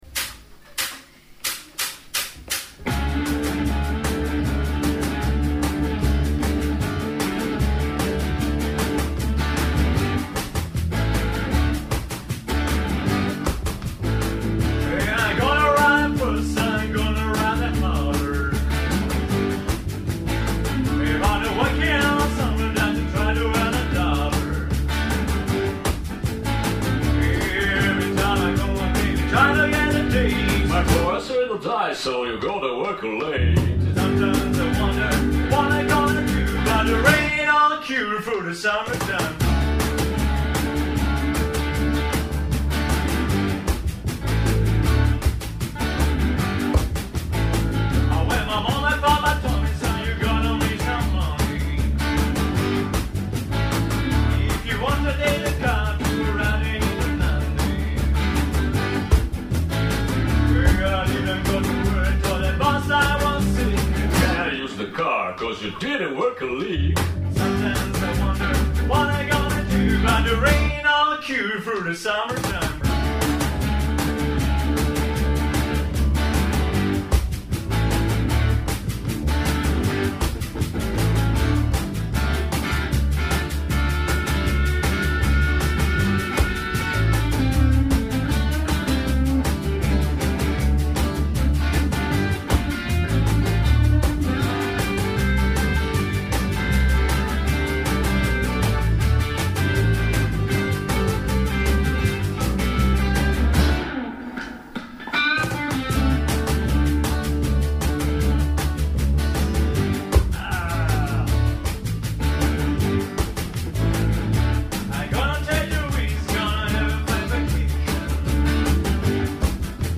voce solista
Registrazioni delle prove: